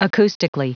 Prononciation du mot acoustically en anglais (fichier audio)
Prononciation du mot : acoustically